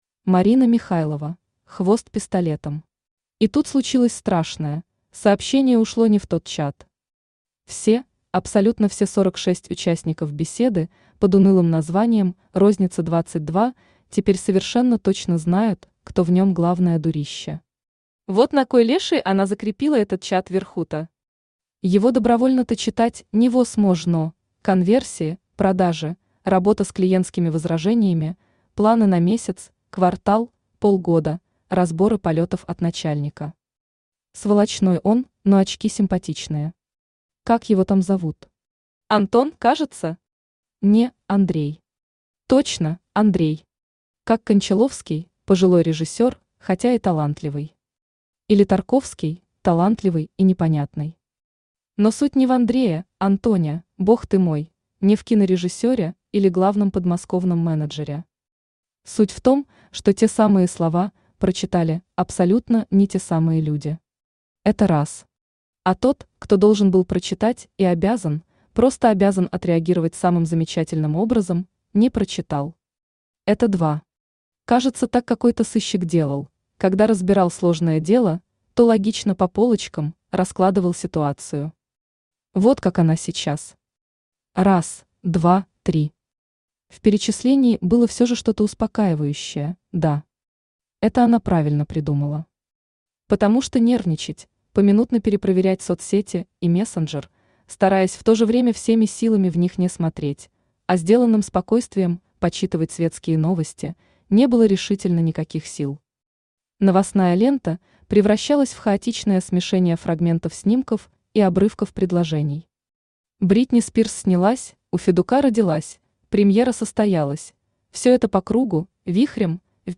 Аудиокнига Хвост пистолетом | Библиотека аудиокниг
Aудиокнига Хвост пистолетом Автор Марина Михайлова Читает аудиокнигу Авточтец ЛитРес.